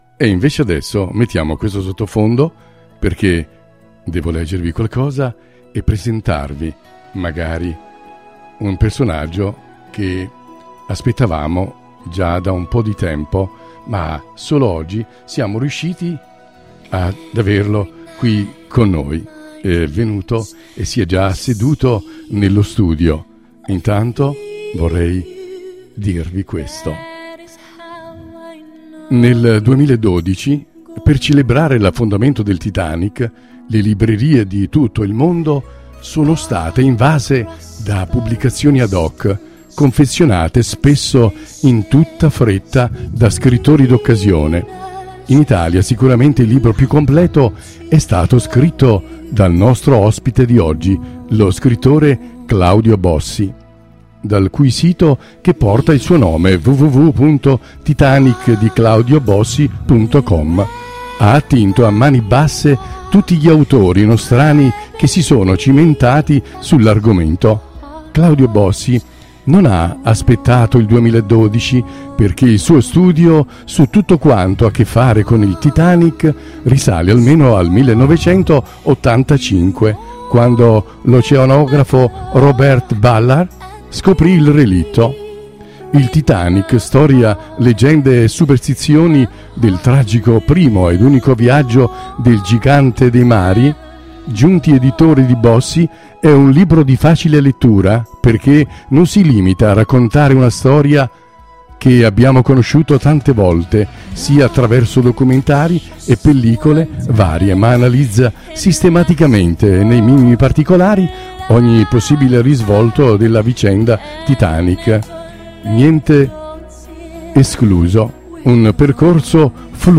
MUSICA E PAROLE, un programma ideato e condotto da Tony Esposito